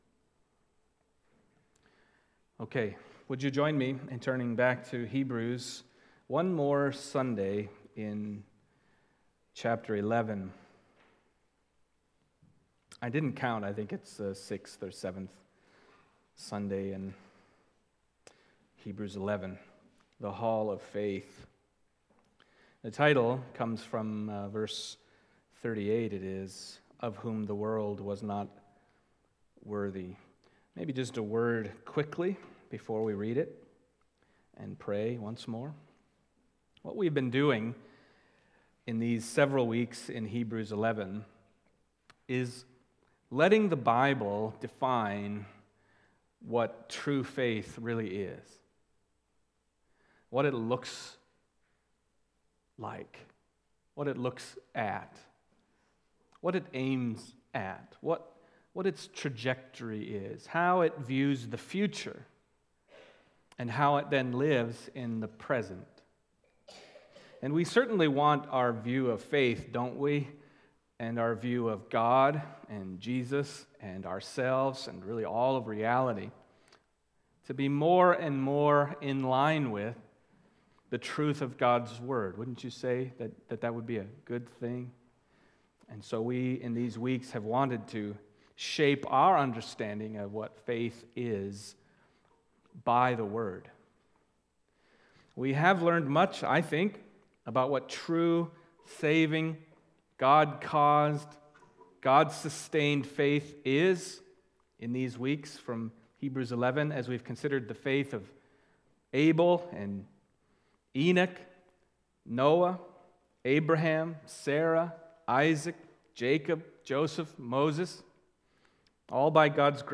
Of Whom the World Was Not Worthy – First Baptist Church – Brookings, South Dakota